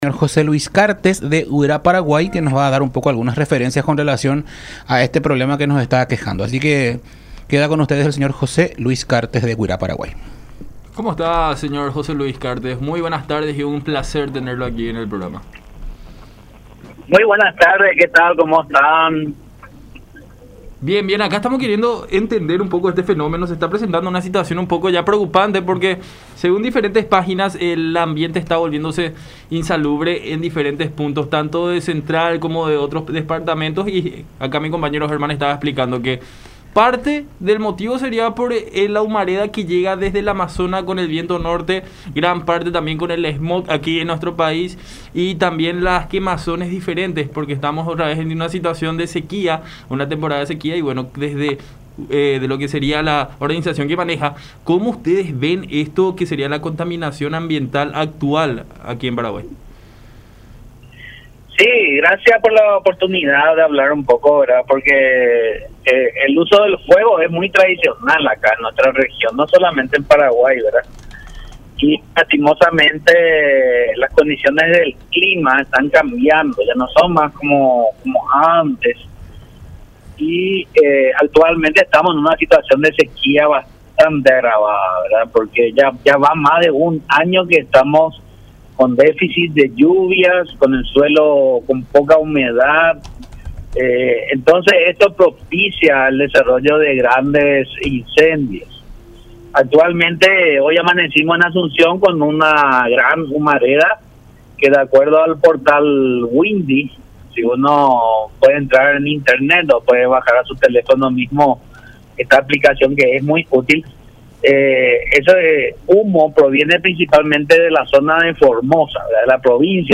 en diálogo con La Unión R800 AM.